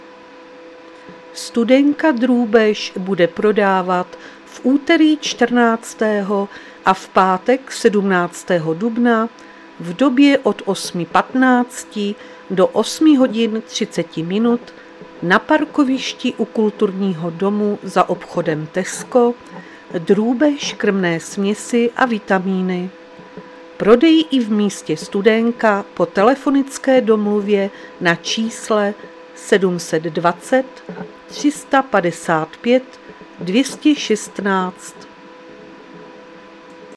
Záznam hlášení místního rozhlasu 13.4.2026